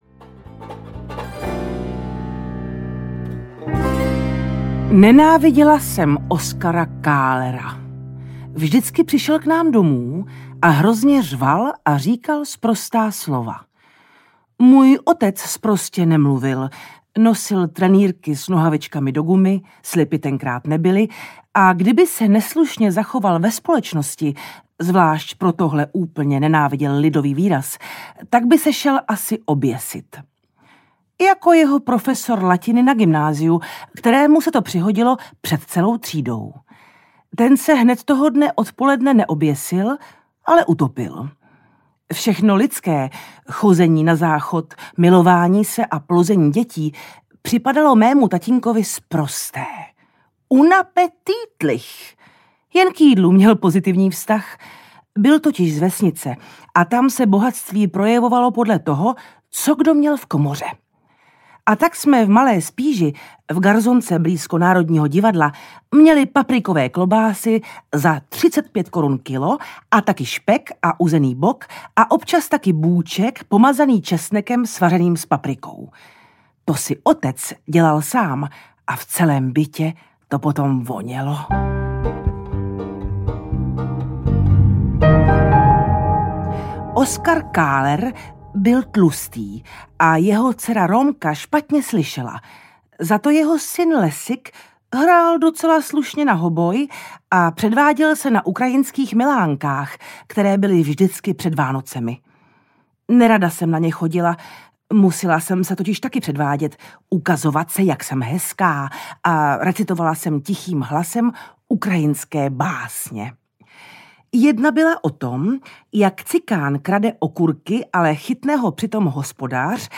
Díky za každé nové ráno audiokniha
Ukázka z knihy
• InterpretLenka Krobotová